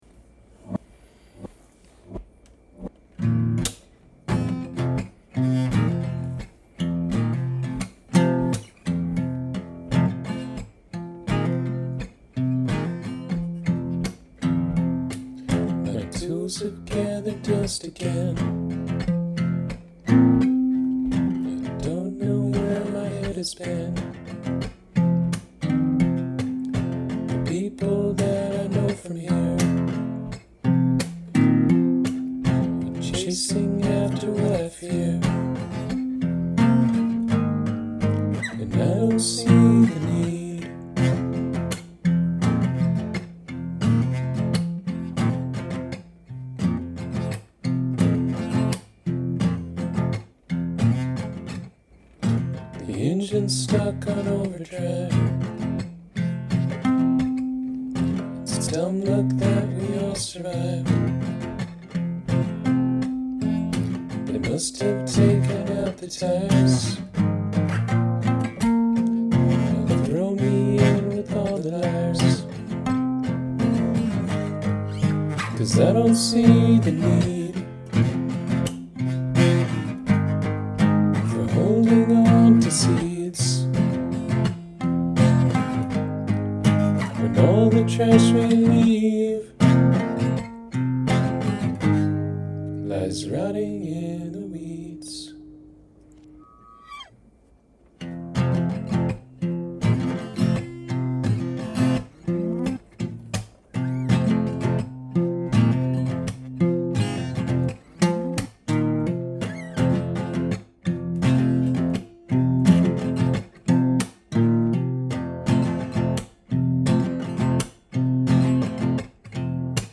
Nice guitar work. Like the random higher notes that you have here.
Digging the strummed accompaniment you've created here, with the floating plucked notes, and the vocal melody. The vocal sounds especially cool on my headphones, one voice in each ear.
I can hear a dirty alt rock / grunge sound.
I like the dark garage rock vibe going on here.
The doubled/chorused vocals is a really nice effect for the arrangement.
Love the double vocals! and the little reverse notes sprinkled in at the end.